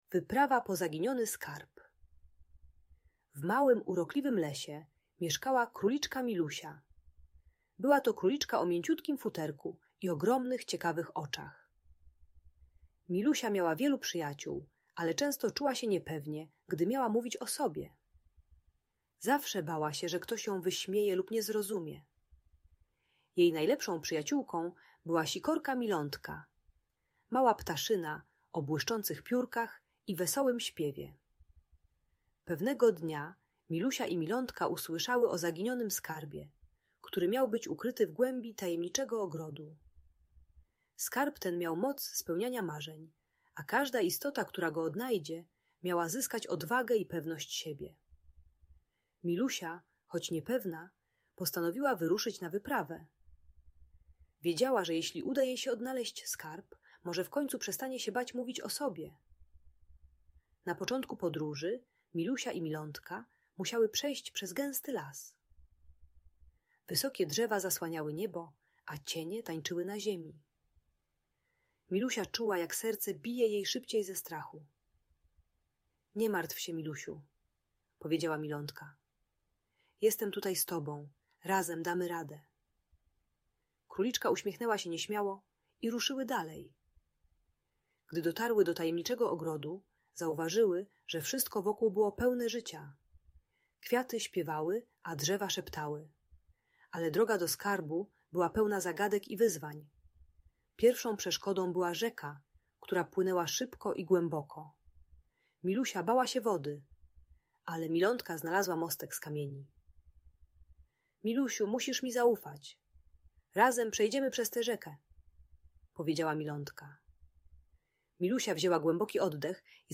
Wyprawa po Zaginiony Skarb: - Lęk wycofanie | Audiobajka